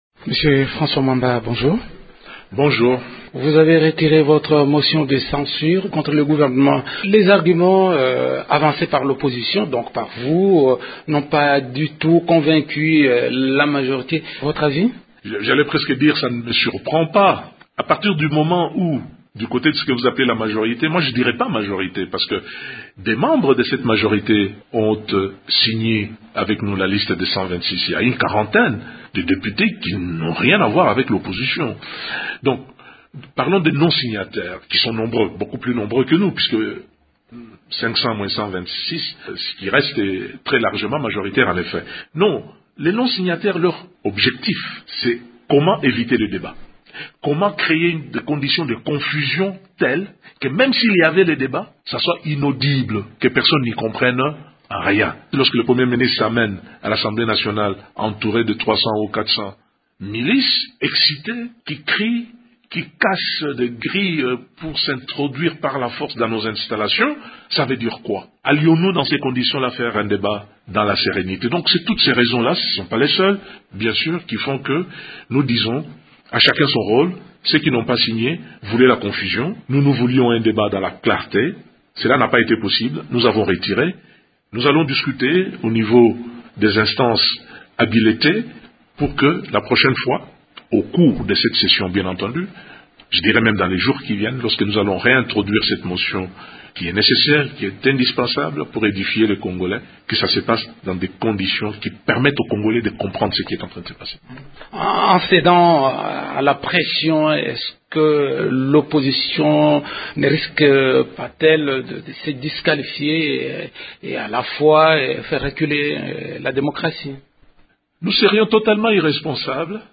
Le président du groupe parlementaire du Mouvement de libération du Congo (MLC) est l’invité de Radio Okapi.